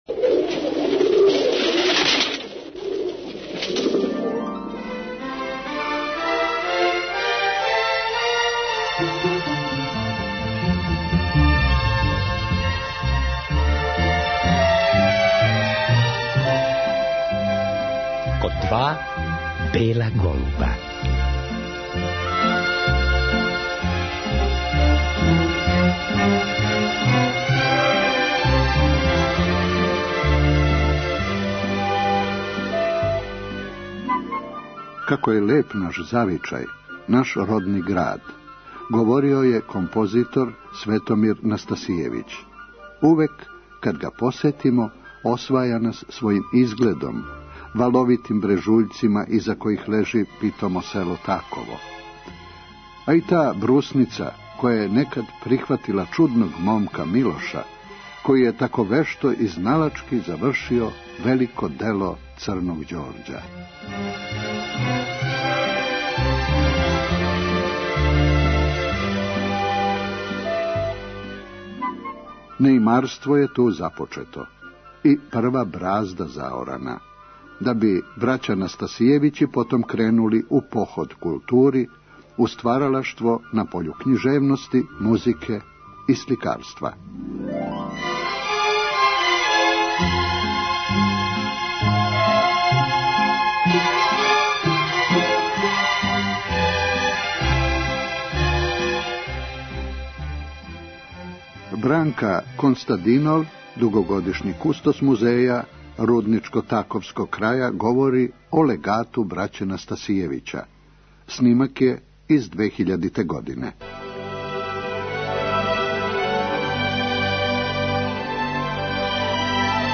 Наша гошћа говори о породици Настасијевић и њеним члановима, о библиотеци коју је породица поседовала и о значају и раду самог легата који садржи велики број драгоцених личних предмета, музичких инструмената, комада намештаја, стотинак уметничких дела међу којима има цртежа и слика, више од 200 фотографија, скоро 500 докумената, позоришних плаката, белешки, преписки и око 1100 књига и записа музичких и сценских дела.